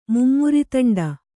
♪ mummutaṇḍa